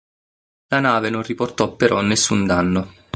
nes‧sùn
/nesˈsun/